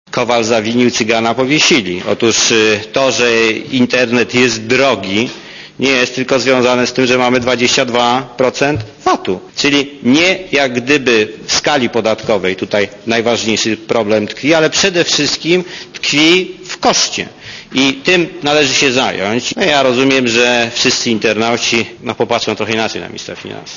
wypowiedz ministra Raczko (minister finansow) :